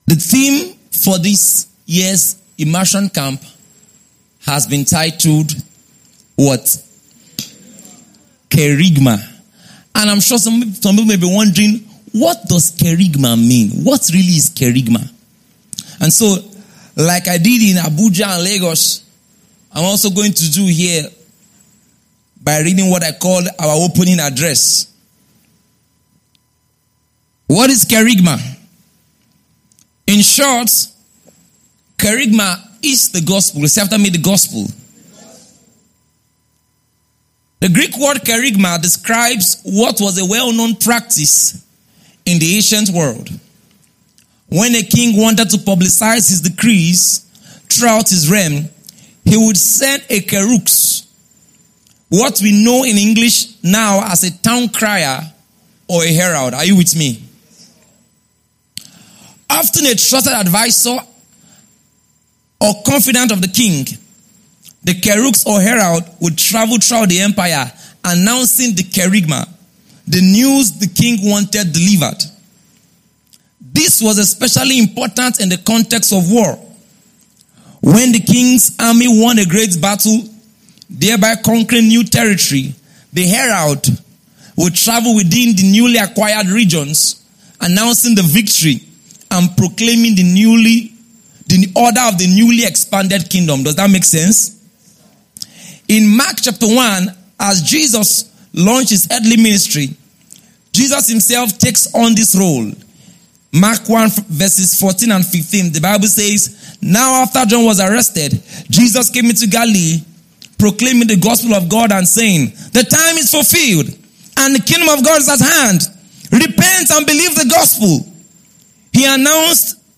Doctrine_of_substitution_TIC24PH_DAY_1_Evening_SessionEdited_compressed (2).mp3